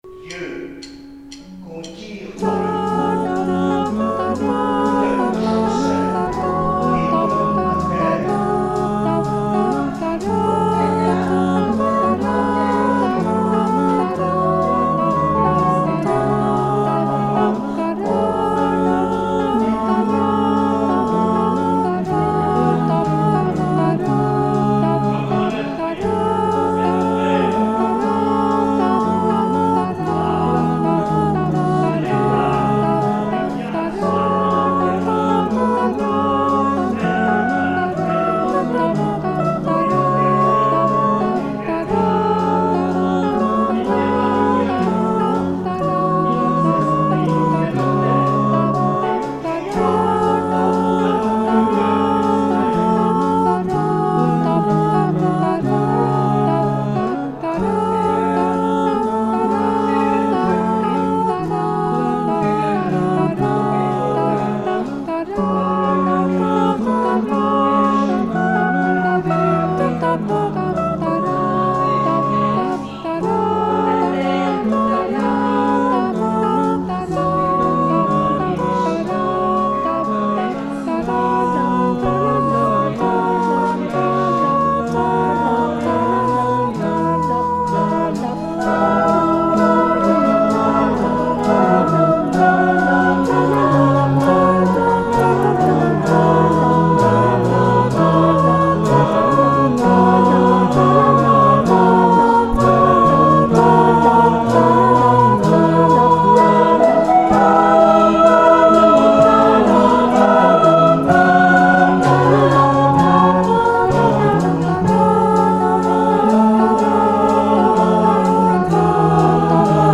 musique station 3 : bande son de montage de la vidéo